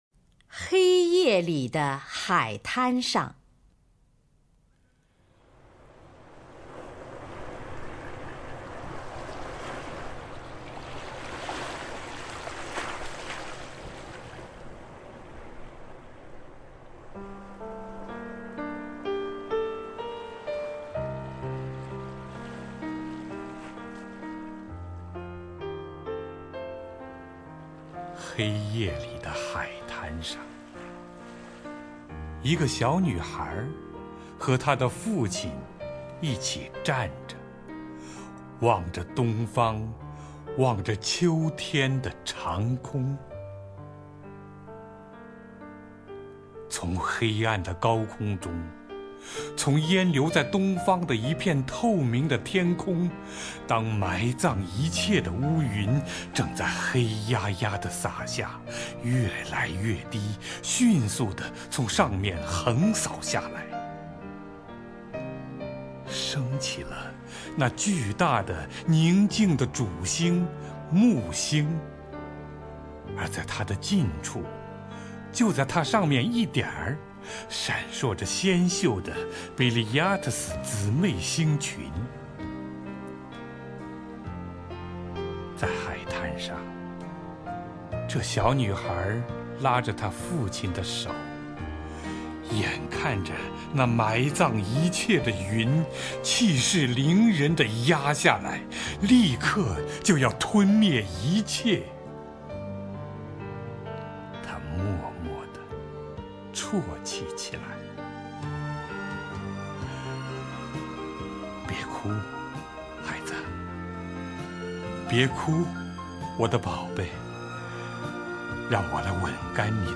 首页 视听 名家朗诵欣赏 乔榛
乔榛朗诵：《黑夜里在海滩上》(（美）沃尔特·惠特曼)